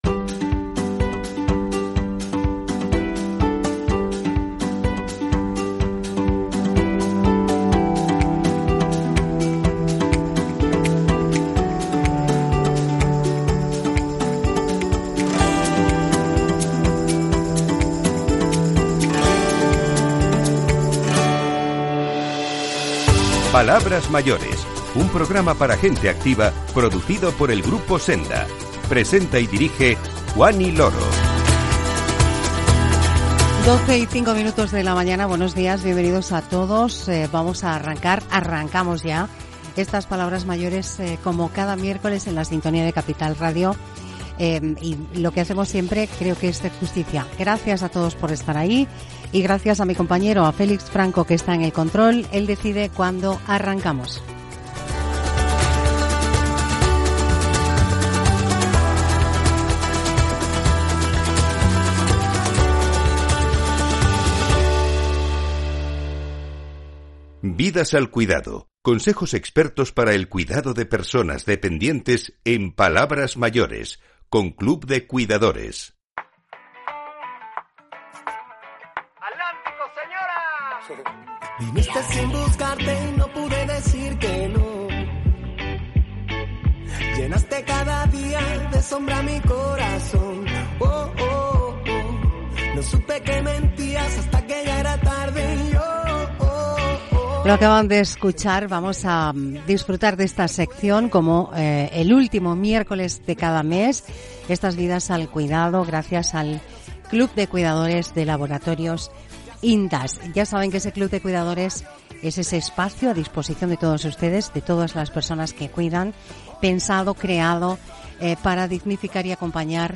Versos para despertar la emoción.